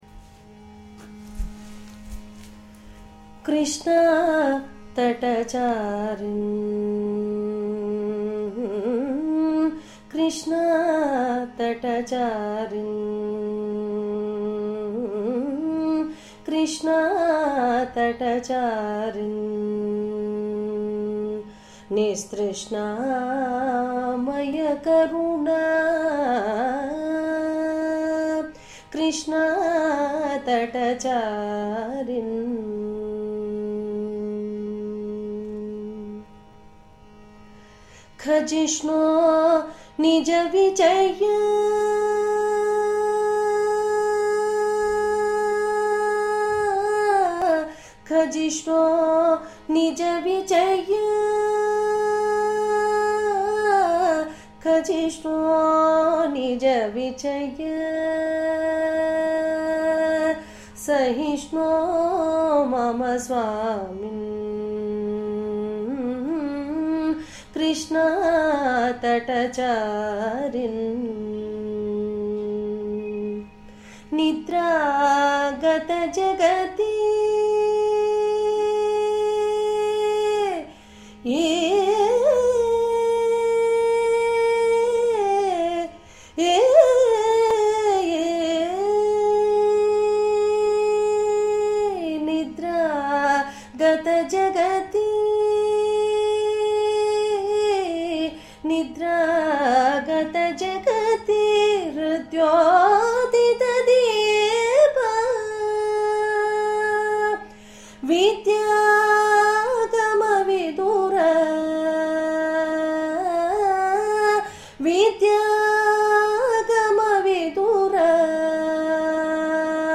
రాగం: సింధు భైరవి
తాళం: ఏకతాళం